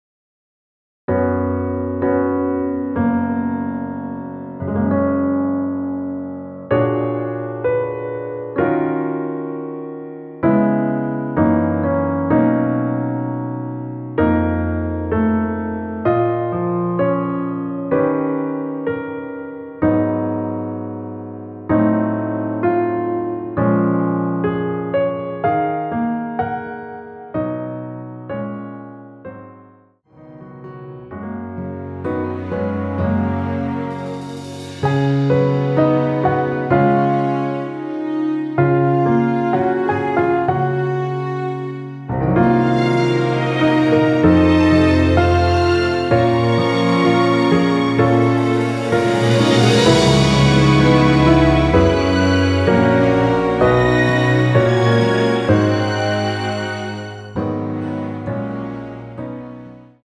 전주 없이 시작하는 곡이라 노래하기 편하게 전주 1마디 만들어 놓았습니다.(미리듣기 확인)
F#
앞부분30초, 뒷부분30초씩 편집해서 올려 드리고 있습니다.
중간에 음이 끈어지고 다시 나오는 이유는